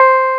CLAVI6 C5.wav